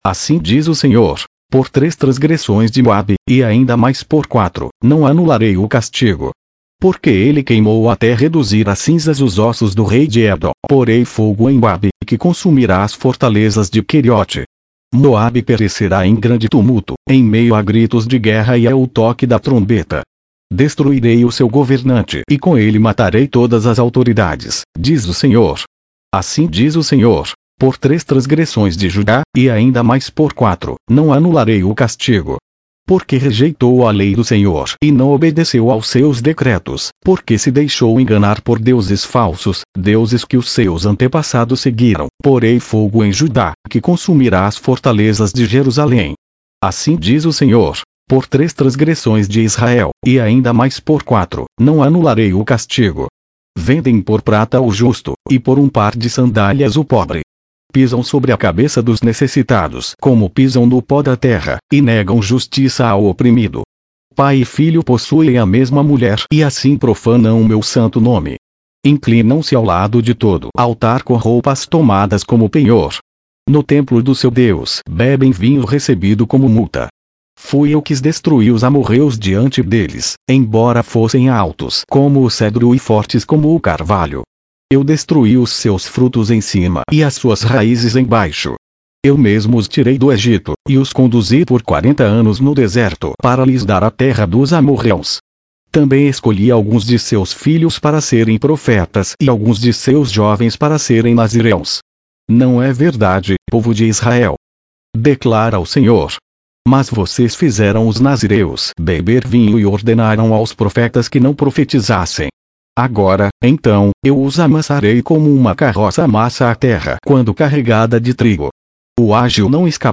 Leitura na versão Nova Versão Internacional - Português